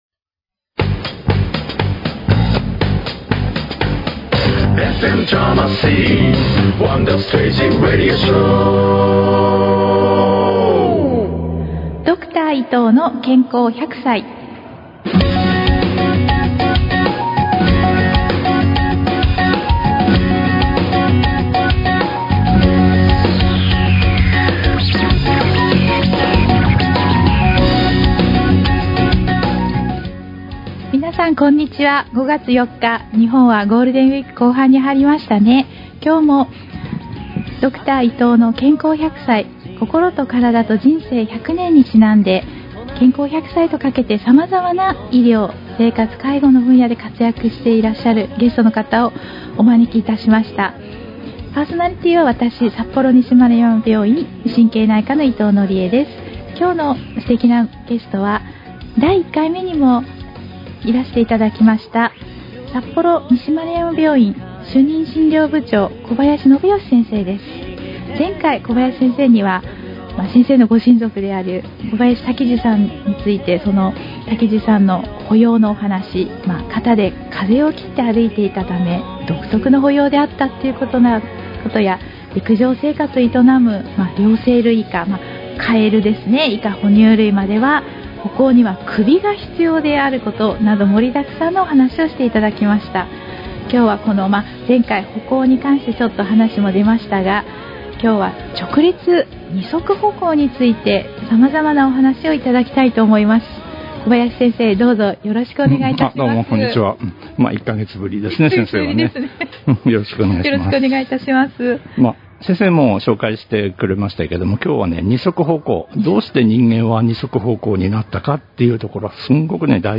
ラジオ放送で流した楽曲は２次利用できないので、曲を外したものです。